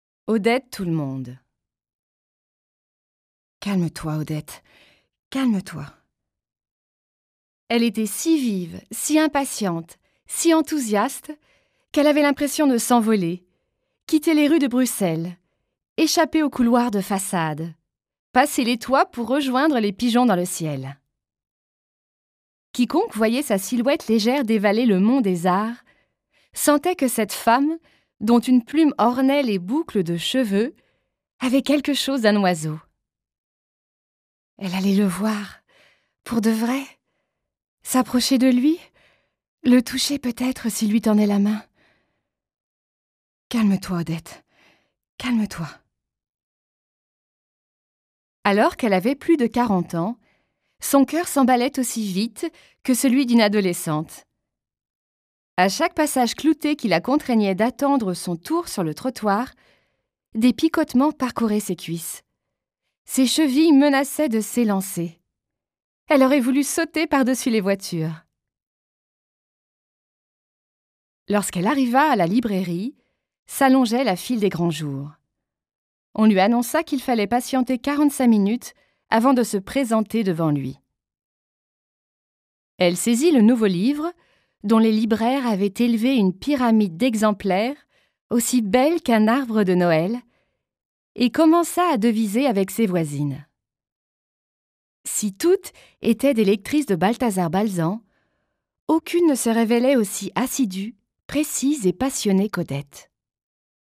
Das Hörbuch zum Sprachen lernen.Ungekürzte Originalfassung / Audio-CD + Textbuch + CD-ROM
Interaktives Hörbuch Französisch